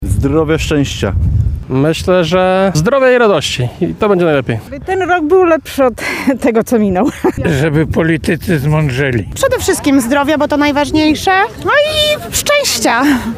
Tak w 2026 rok weszli mieszkańcy Lublina.